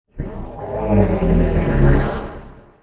Beast Deep Groan Sound Button - Free Download & Play